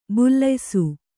♪ bullaysu